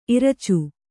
♪ iracu